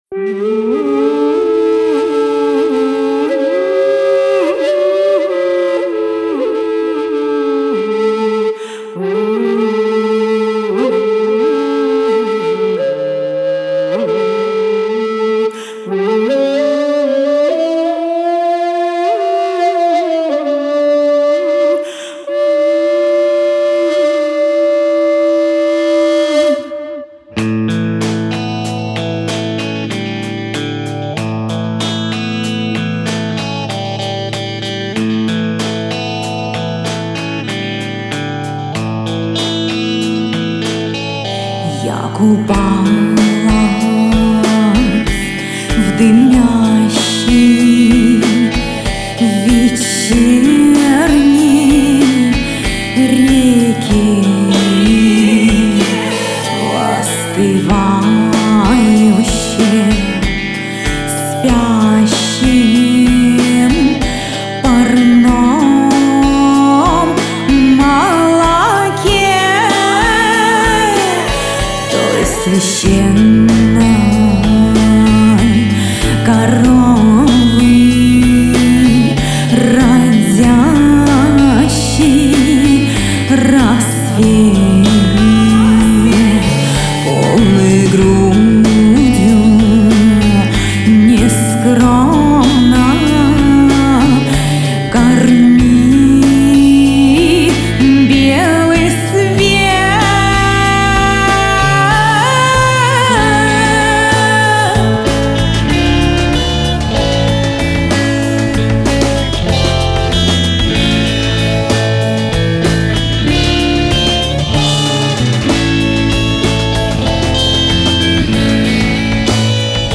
Genre: Folklore.